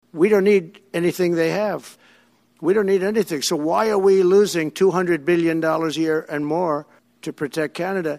During a press conference Tuesday, the President-Elect described the border between the two nations as an “artificially drawn line,” claiming it costs the U.S. over $200 billion annually to protect Canada without receiving adequate benefits in return.